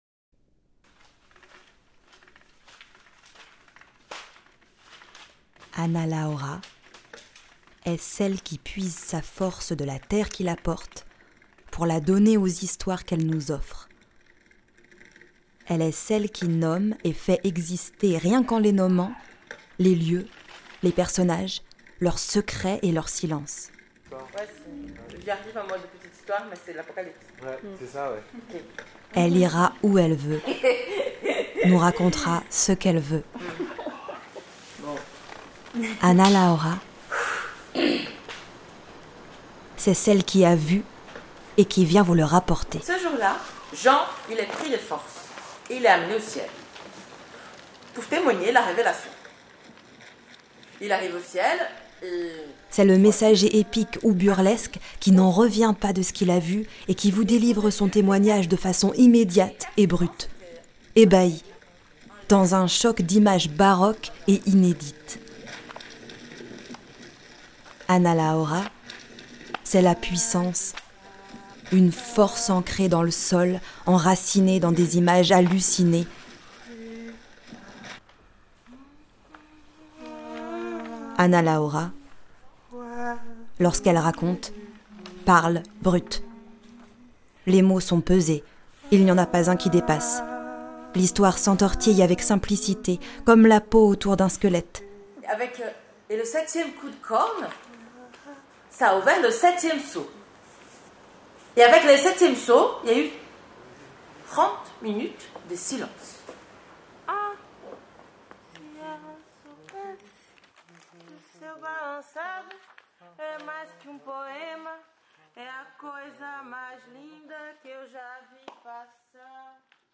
CAPSULES SONORES DU LABO 5 AVANT ENVOL
Des portraits à la volée – en toute simplicité -, pour entendre des univers et des singularités artistiques partagées par la voix d’un·e autre.